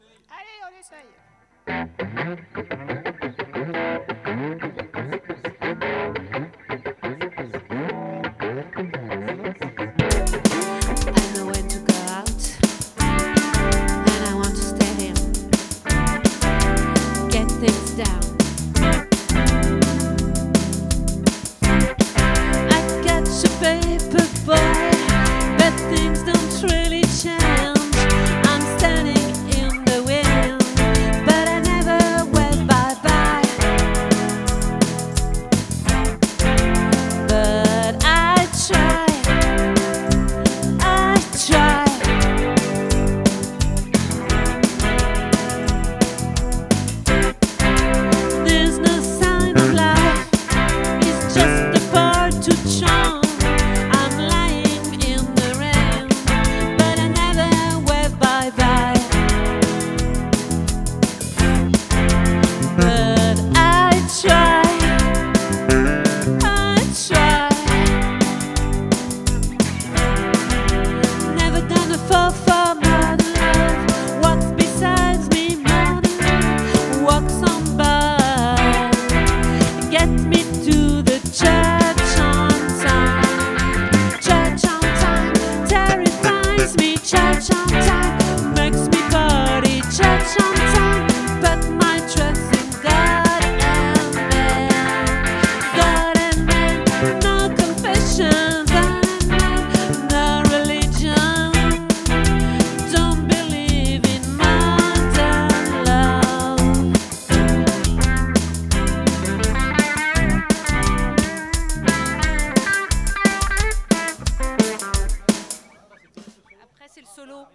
🏠 Accueil Repetitions Records_2025_03_03